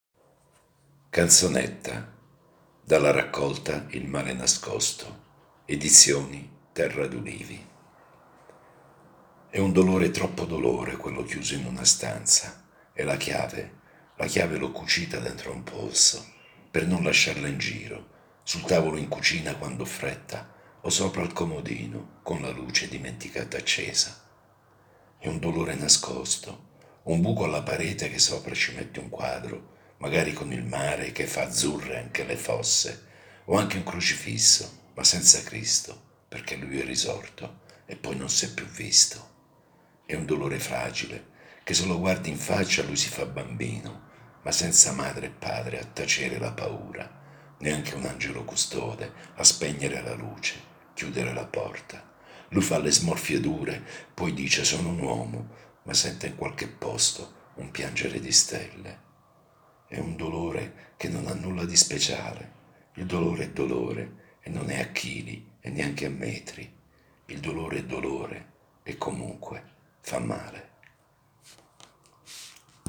Solo un’avvertenza: la voce narrante è quella di un lettore comune e non l’espressione professionale di un attore, così come l’ambiente operativo che non è uno studio di registrazione.